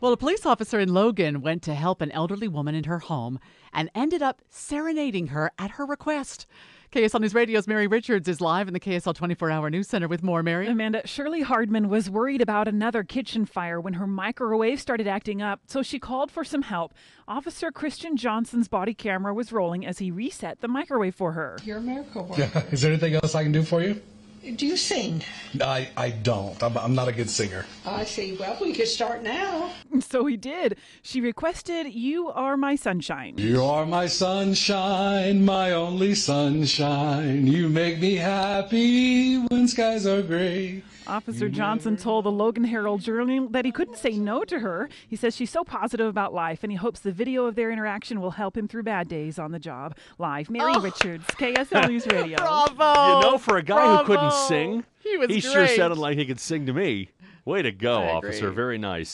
Logan police officer sings on the job
It wasn't a singing telegram, but a singing police officer in Logan who arrived to help a woman in her home.